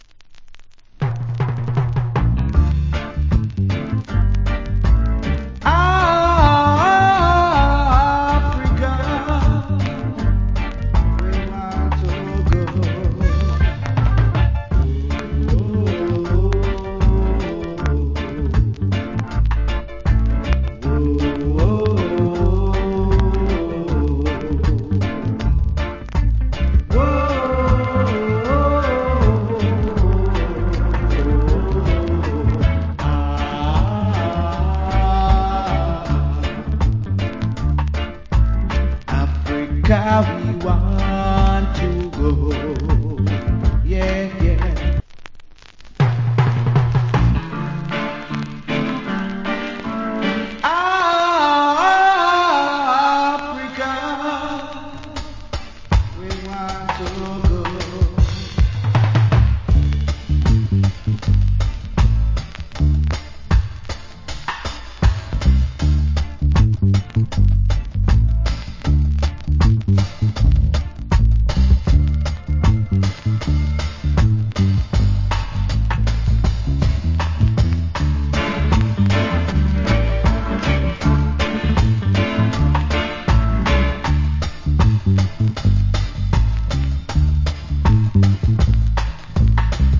Cool Roots Rock Vocal.